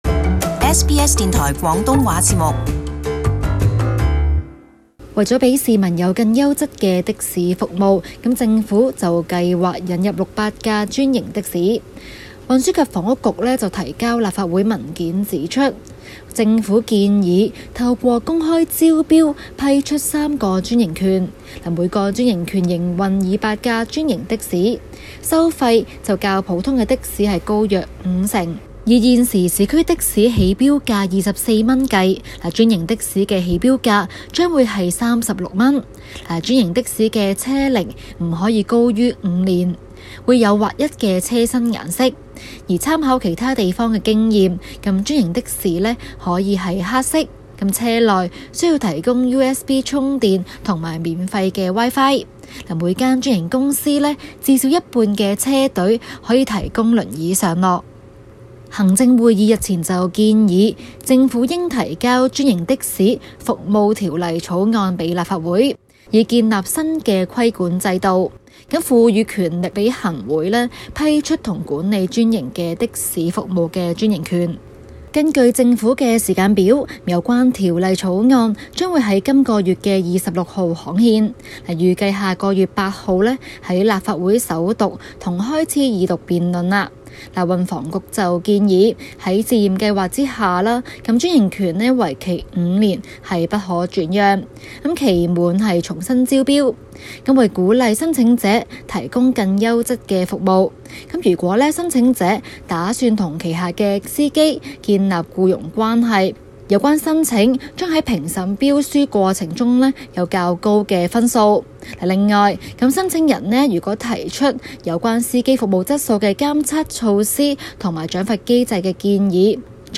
【中港快訊】港府是否對專營的士監管過嚴？